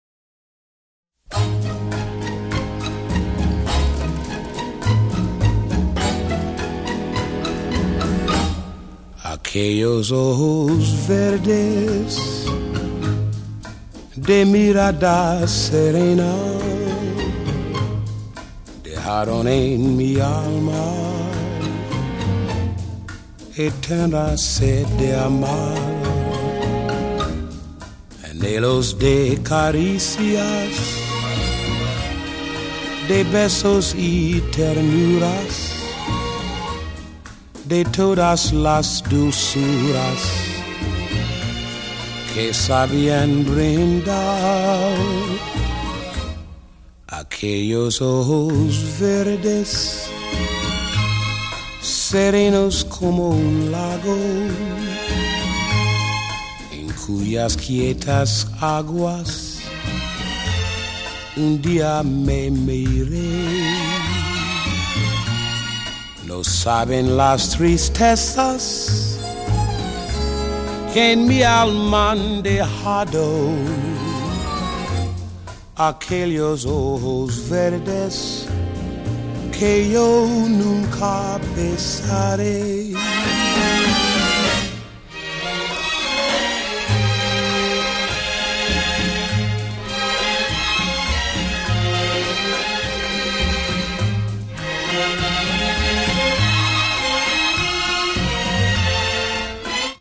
如徐風般的美聲，在各小節線與過門流轉的完美性，足以成為所有聲樂演唱者的典範。